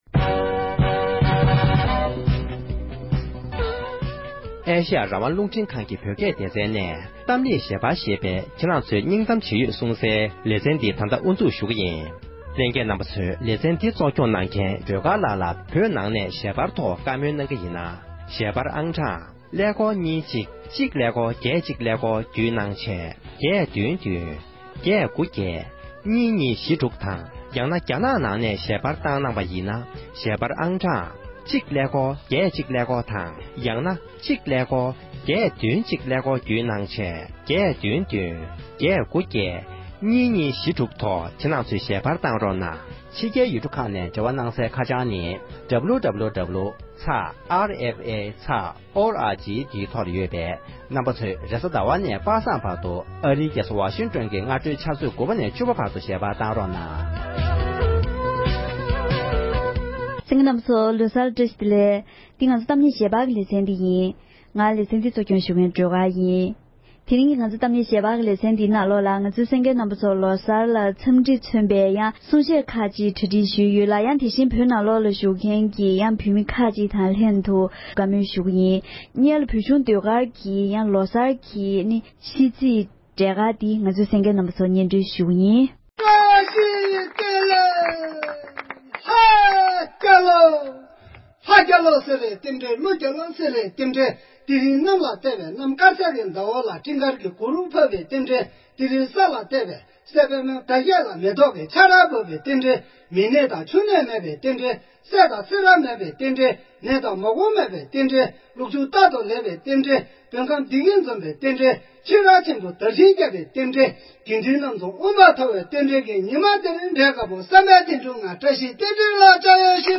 བོད་ནས་གཏམ་གླེང་ཞལ་པར་གྱི་ལེ་ཚན་བརྒྱུད་གཞིས་བྱེས་བོད་མི་ཡོངས་ལ་གནམ་ལོ་གསར་ཚེས་ཀྱི་འཚམས་འདྲི།